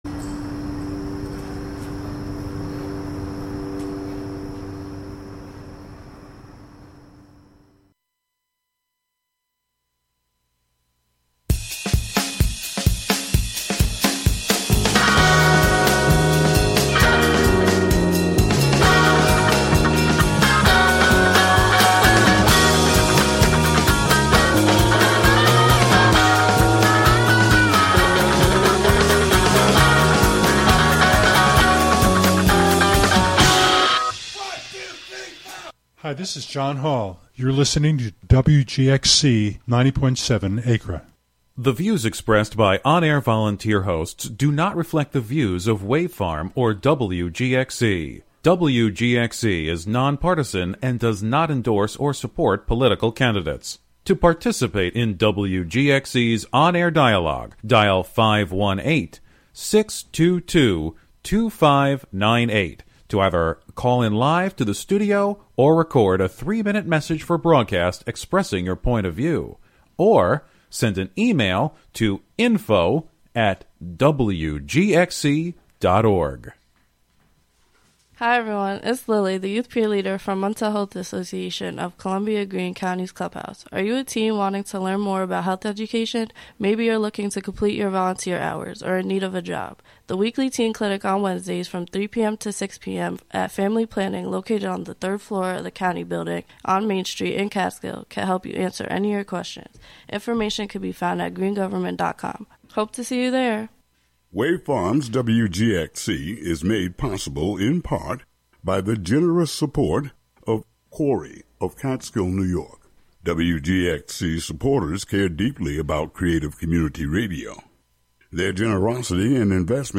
brings you sounds from raves and clubs around the world